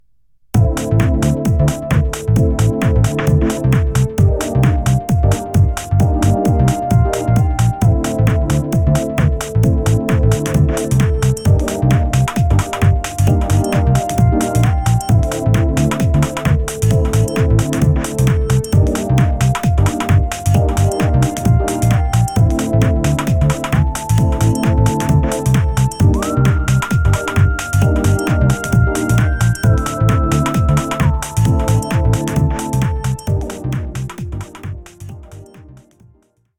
Attached is a realtime recorded clip of my budget realtek chip on my budget motherboard playing 9 channels of asio sound, using 6 different virtual instruments and 2 mastering plugins. At 16.6ms latency (DirectX).